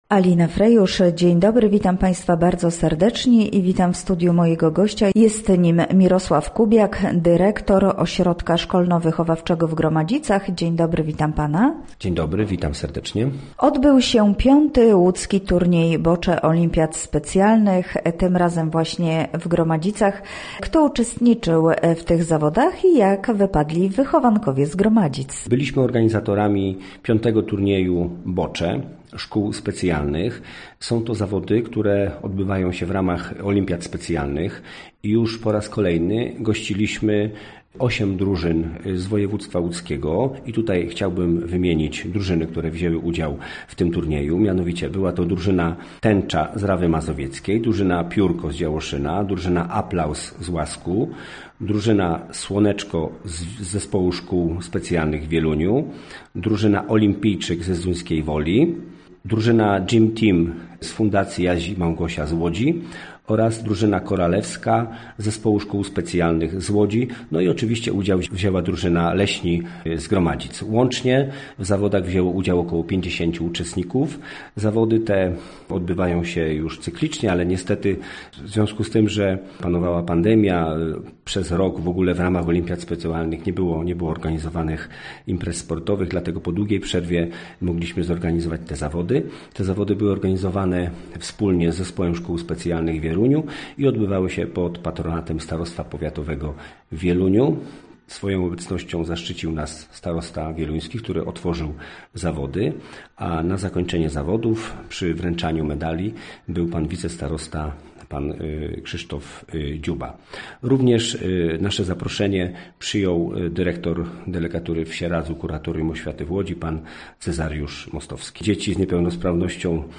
Nasz gość mówi o tym Turnieju oraz o wyjeździe wychowanków do Koła Gospodyń Wiejskich w Kałużach, które zorganizowało zbiórkę na rzecz Ośrodka.